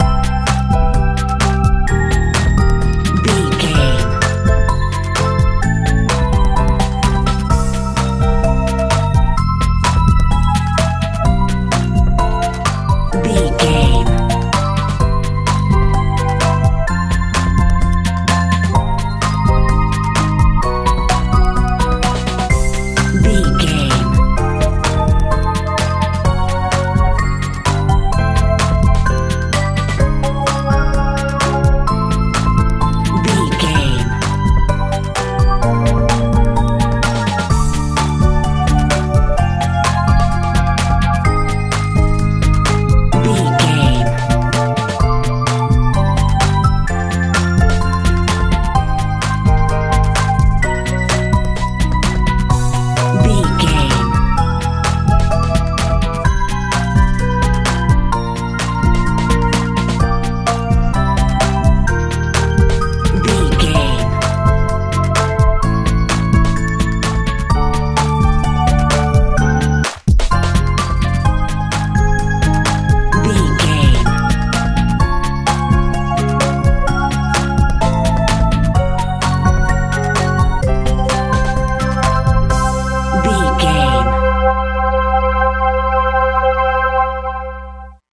pop dance feel
Ionian/Major
joyful
bass guitar
synthesiser
drums
dreamy